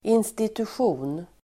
Uttal: [institusj'o:n]